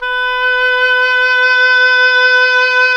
WND OBOE2 06.wav